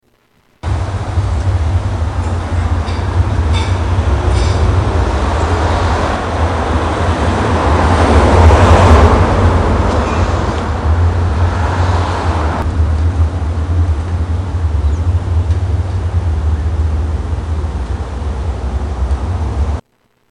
Cape Cod Street at Morning